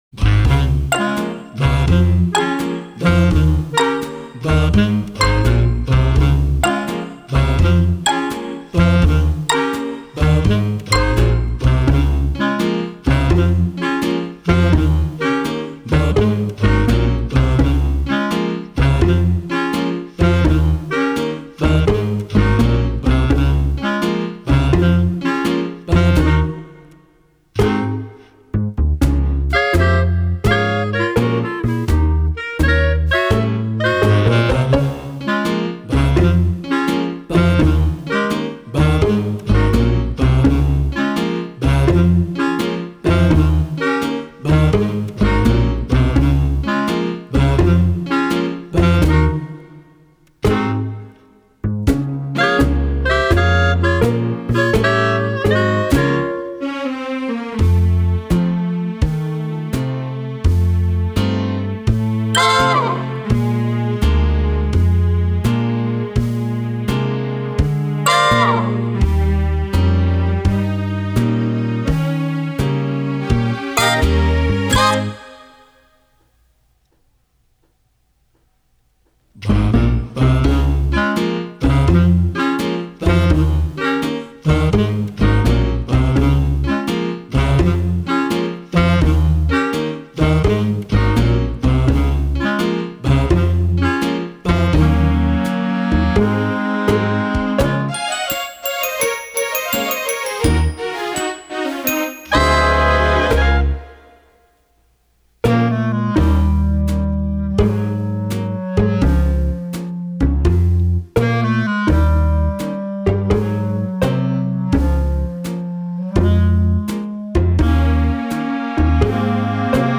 Karaoke VA